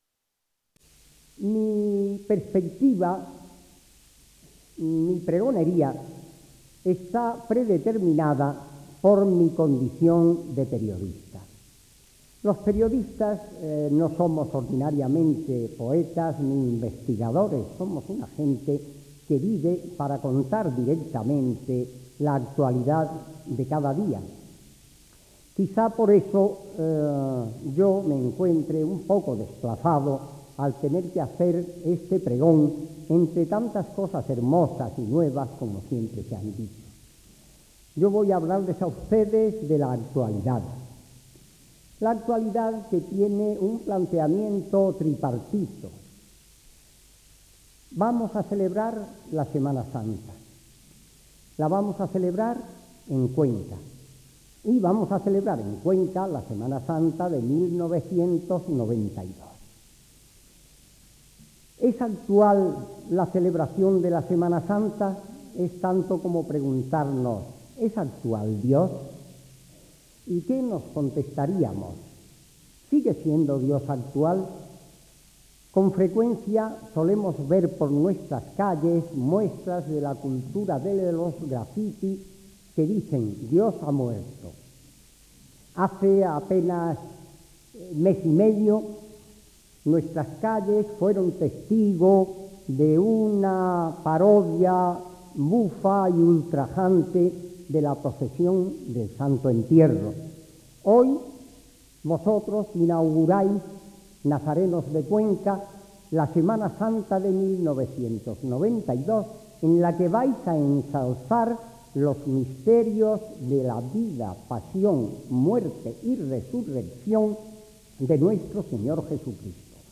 pregon-a.-de-castro-defi.wav